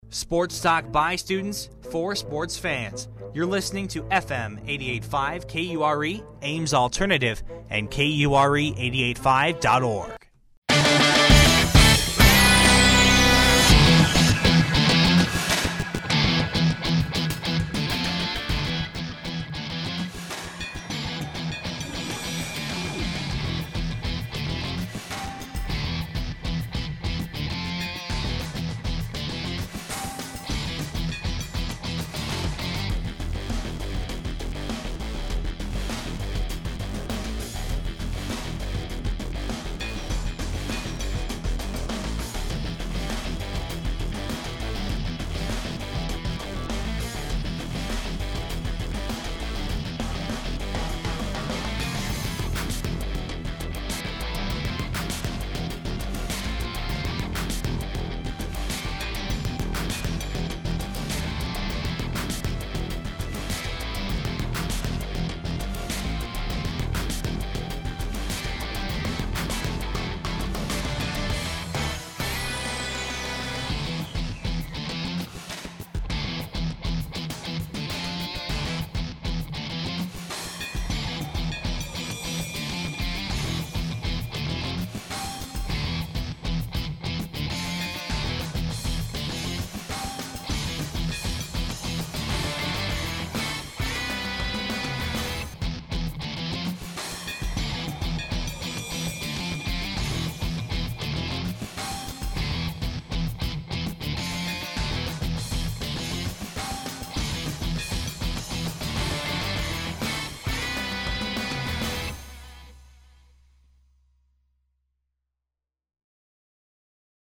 Legal ID with Sports Update
Category: Radio   Right: Personal